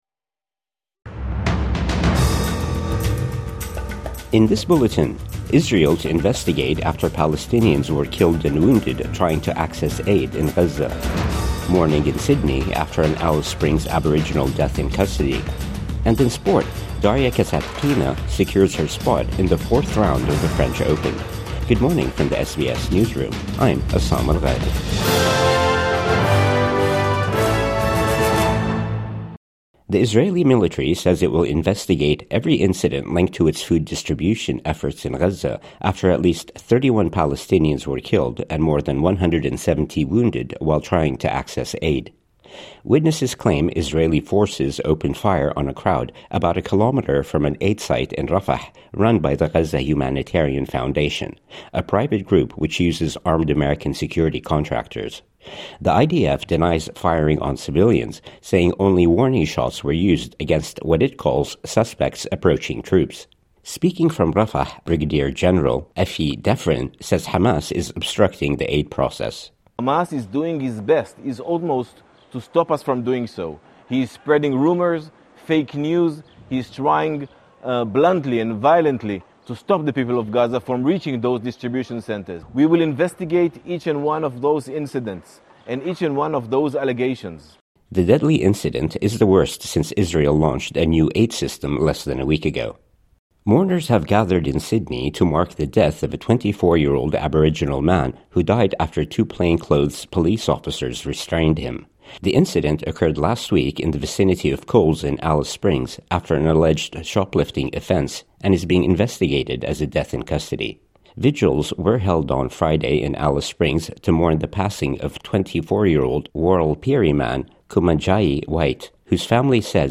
Israel to investigate after Palestinians killed trying to access aid | Morning News Bulletin 2 June 2025